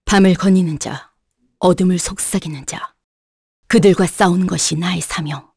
Seria-vox-get_kr.wav